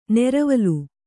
♪ neravalu